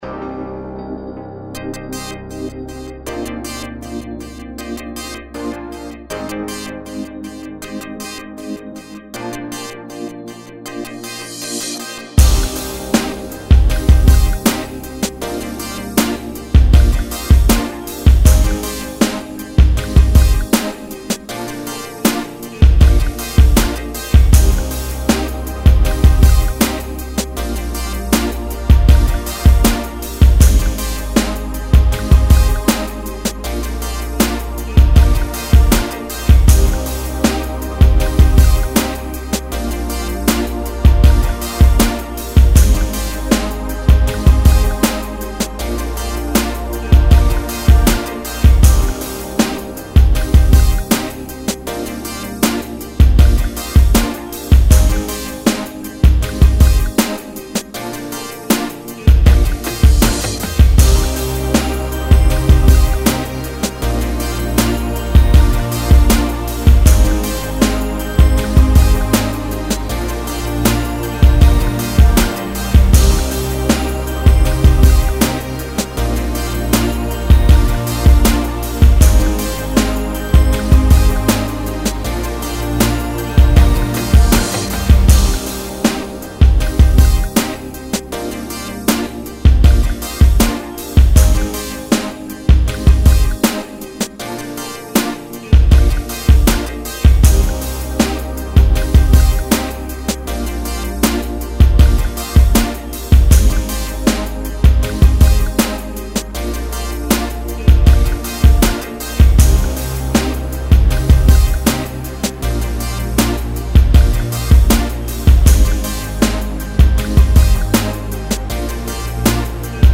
79 BPM.